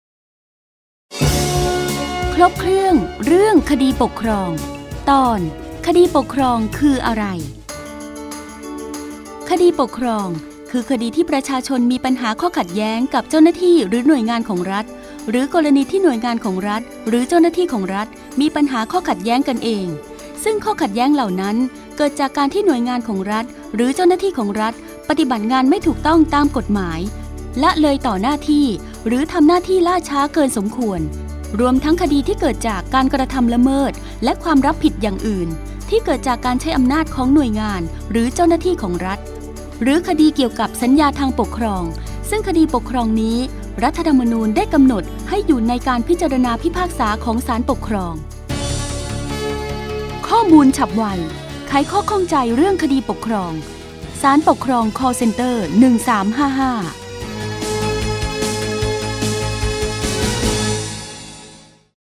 สารคดีวิทยุ ชุดครบเครื่องเรื่องคดีปกครอง ตอนคดีปกครองคืออะไร
คำสำคัญ : สารคดีวิทยุ, คดีปกครองคืออะไร, ครบเครื่องเรื่องคดีปกครอง